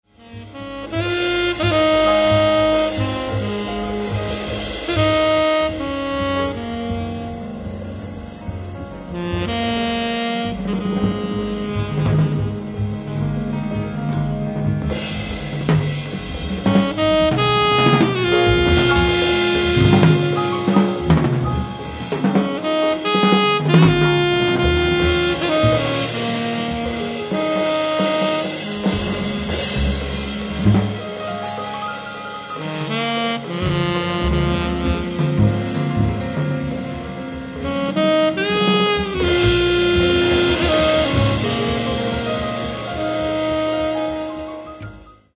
tenor saxophone
acoustic bass
drums
piano
trumpet